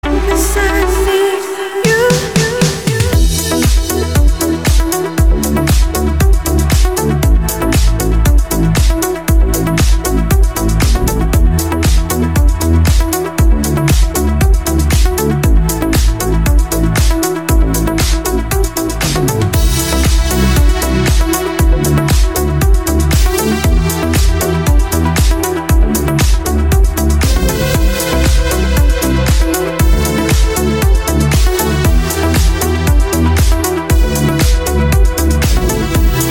• Качество: 320, Stereo
женский вокал
remix
Стиль: deep house, nu disco